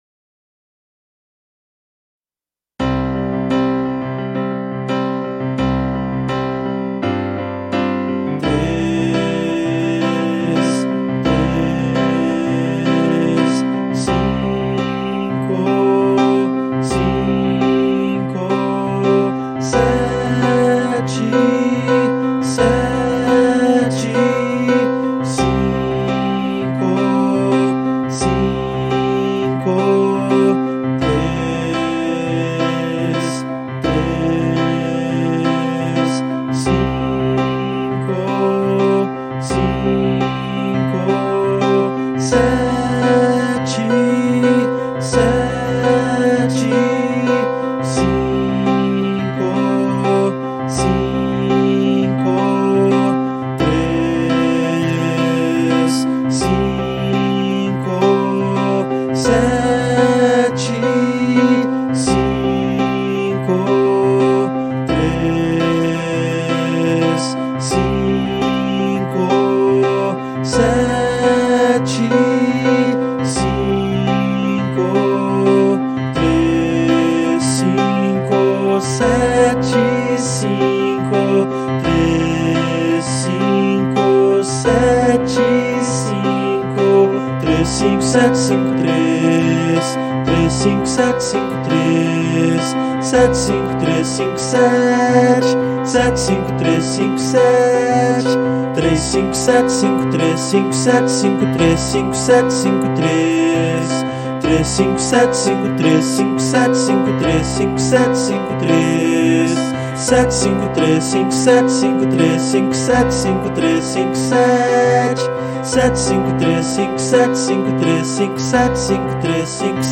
Exercício de Triade - 3 5 7 Outros MP3 1. 07_Exercício de Triade - 3 5 7.mp3 cloud_download